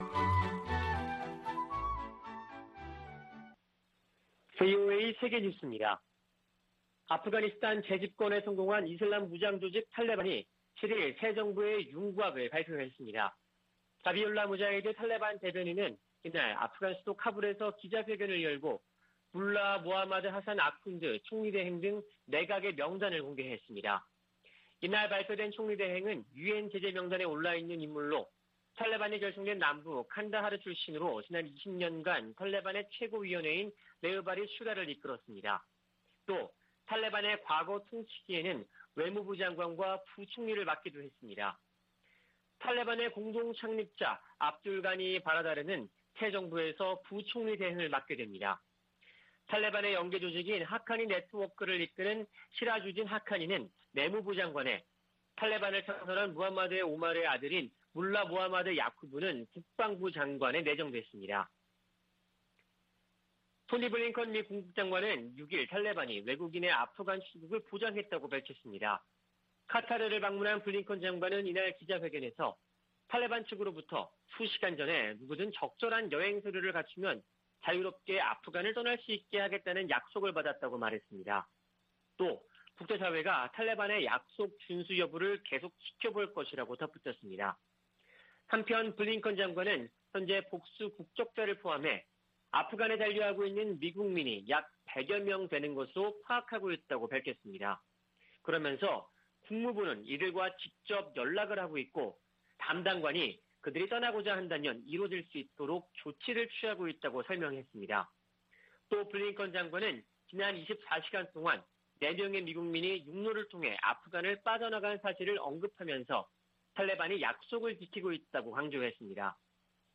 VOA 한국어 아침 뉴스 프로그램 '워싱턴 뉴스 광장' 2021년 9월 8일 방송입니다. 북한이 핵무기와 미사일 관련 국제 규칙을 노골적으로 무시하고 있다고 NATOㆍ나토 사무총장이 밝혔습니다. 오는 14일 개막하는 제 76차 유엔총회에서도 북한 핵 문제가 주요 안건으로 다뤄질 전망입니다. 아프가니스탄을 장악한 탈레반이 미군 무기를 북한에 판매하지 않을 것이라고 밝혔습니다.